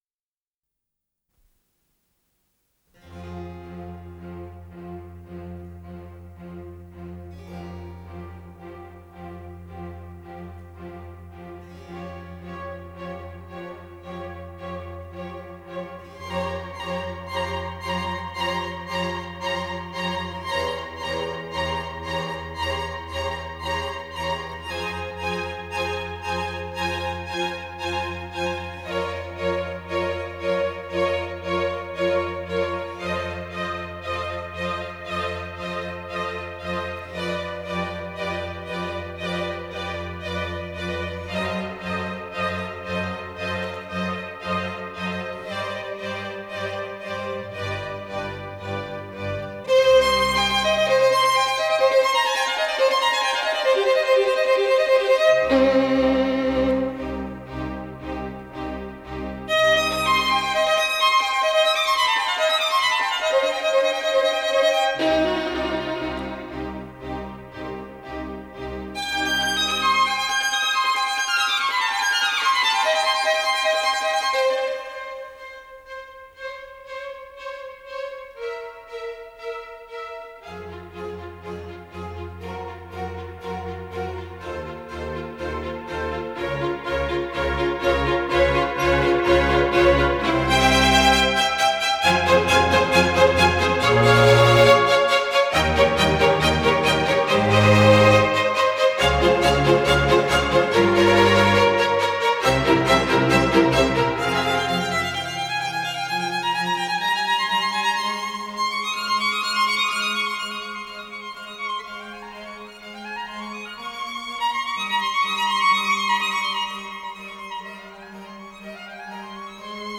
Исполнитель: Сергей Стадлер - скрипка
4 концерта для скрипки с оркестром